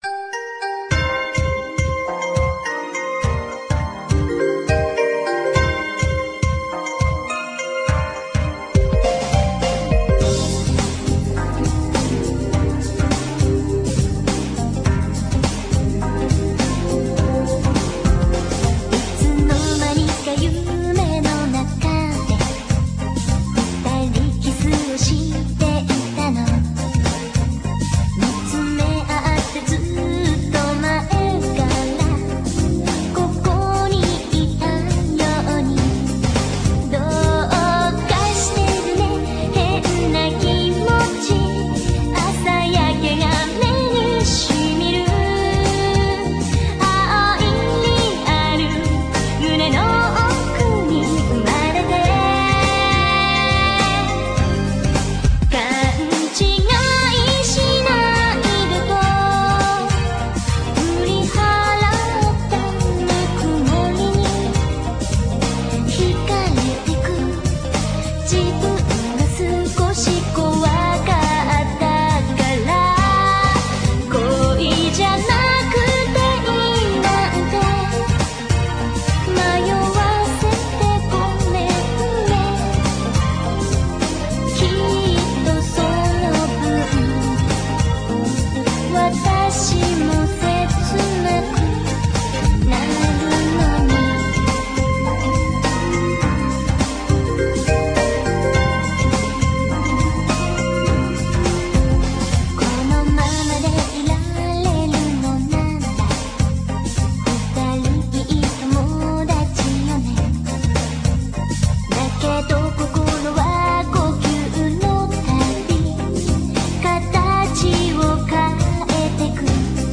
the ending theme